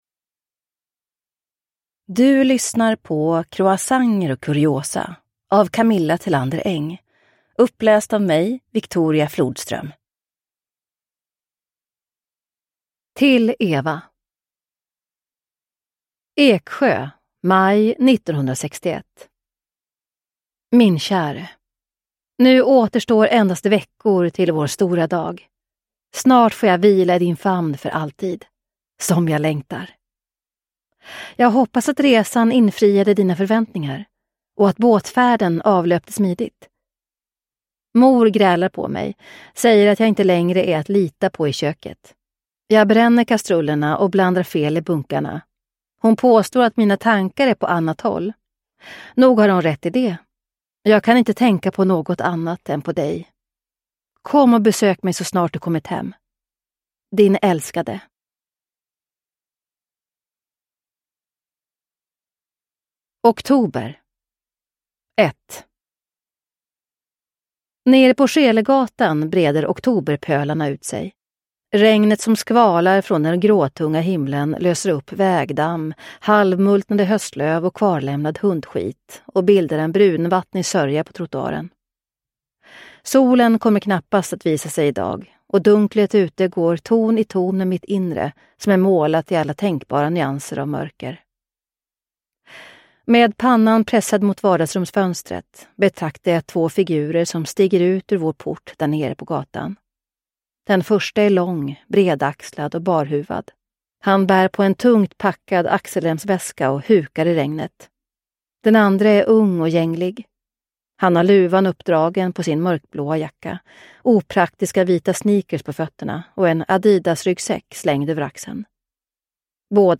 Croissanter och kuriosa – Ljudbok – Laddas ner